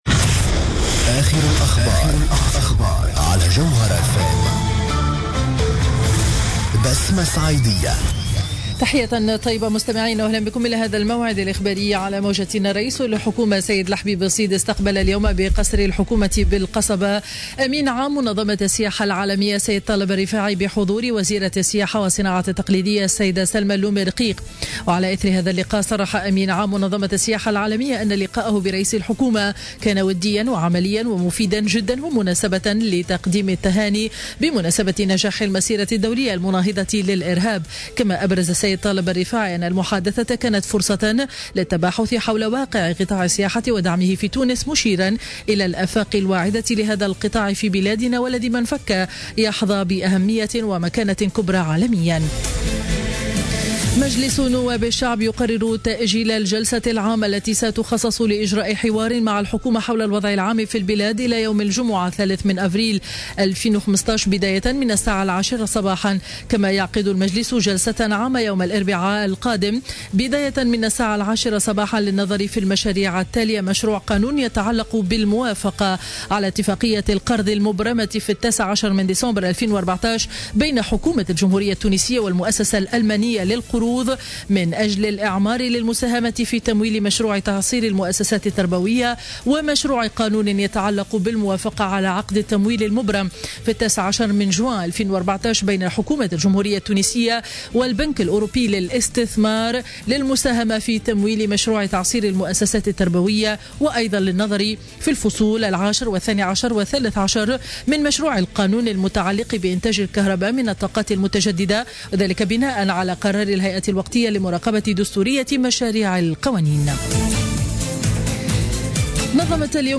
نشرة أخبار منتصف النهار ليوم الاثنين 30 مارس 2015